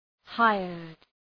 hired.mp3